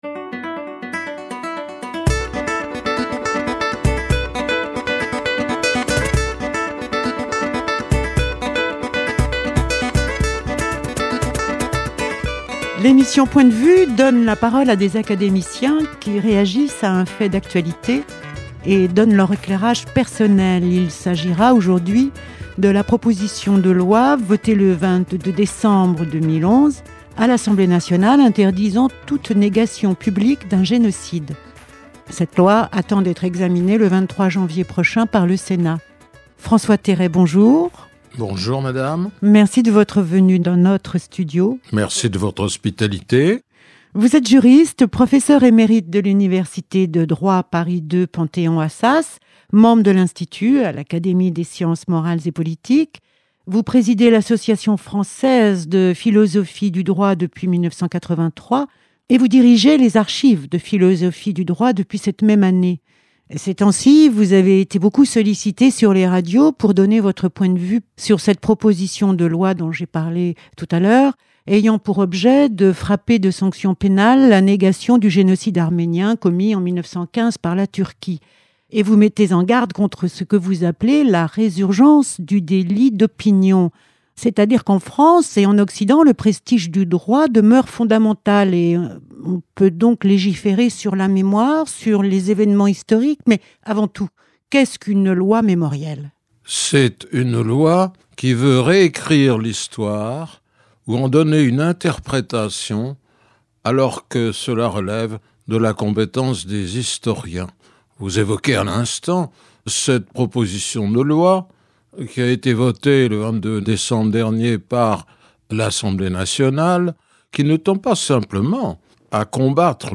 L’émission « Point de vue » permet à un académicien de donner son analyse personnelle sur un fait d’actualité. Le juriste François Terré, de l’Académie des Sciences morales et politiques, réagit à la proposition de loi votée le 22 décembre 2011 interdisant toute négation publique d’un génocide, dont celui des Arméniens en 1915. Il rappelle la définition d’un génocide, s’inquiète des lois dites mémorielles et s’interroge sur les liens entre le Droit et l’Histoire.